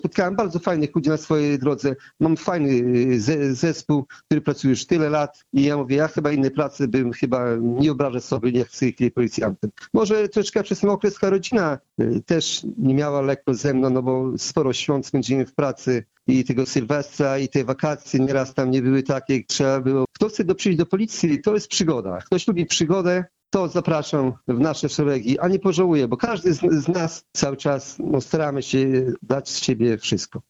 Podczas rozmowy jubilat opowiadał między innymi o swojej pracy. O zdarzeniach z jakimi się potykał i zmianach, jakie na przestrzeni lat zaszły w jego formacji i Suwałkach.